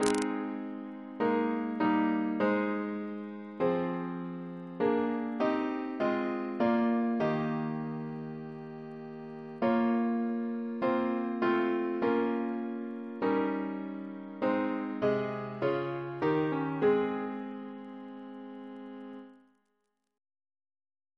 Double chant in A♭ Composer: Edwin George Monk (1819-1900), Organist of York Minster Reference psalters: ACB: 239; CWP: 18; RSCM: 98